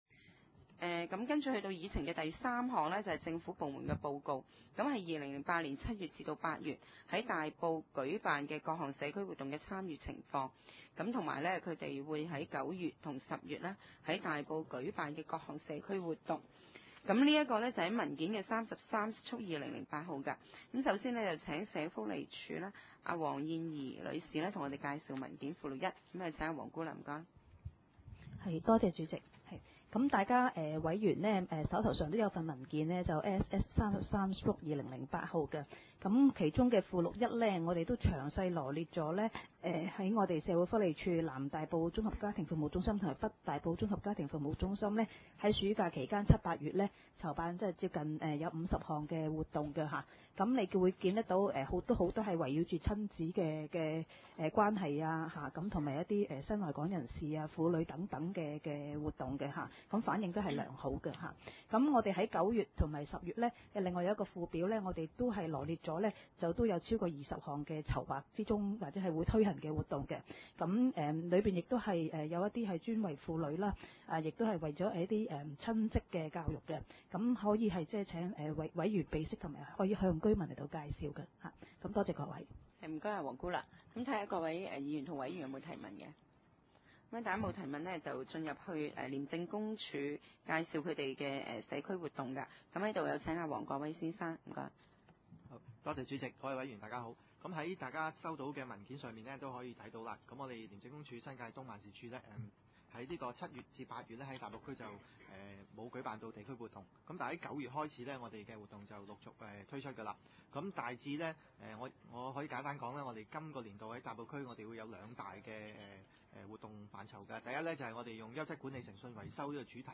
大埔區議會社會服務委員會2008年第五次會議
地點：大埔區議會秘書處會議室